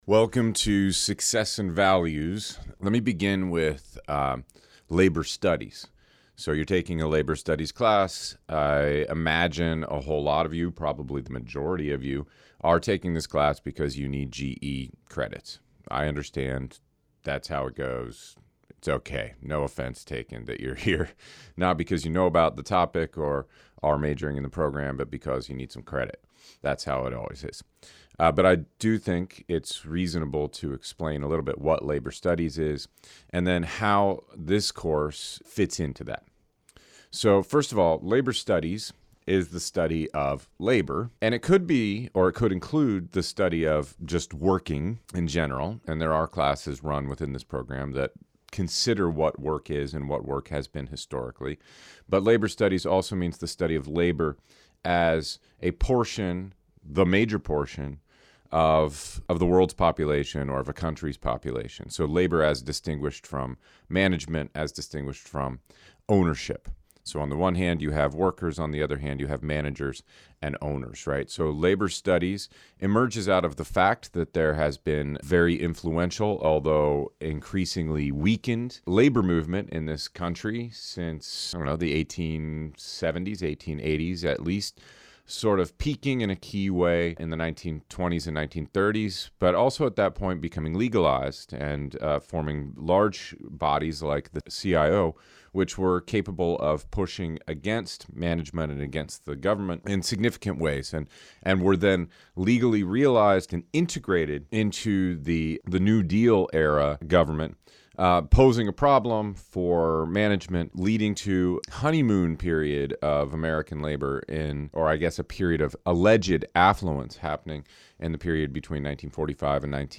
Intro to Ideology audio lecture (MM)